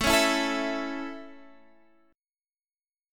Listen to A strummed